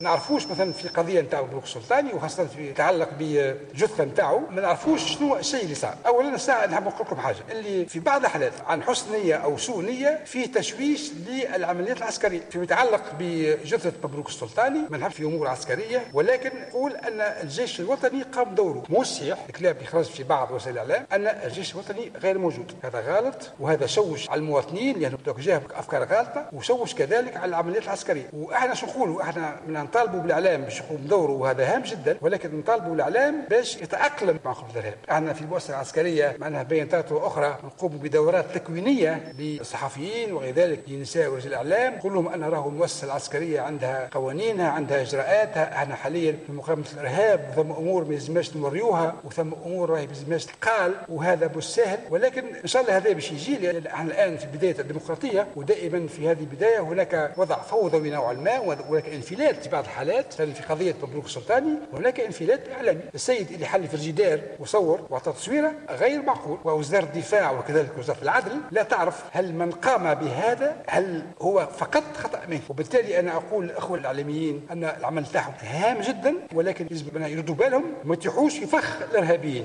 وقال اليوم في جلسة عامة أمام النواب: " نحن لا نعلم عما إذا كان تصوير رأس الشهيد مقطوعة مجرد خطأ مهني"، محذرا الإعلاميين من السقوط في فخ الإرهابيين، بحسب تعبيره. وأكد وزير الدفاع على أن الجيش الوطني قام بدوره فى هذه الحادثة، وطالب الإعلاميين بالتأقلم أكثر مع خصوصيات تغطية الأحداث الإرهابية.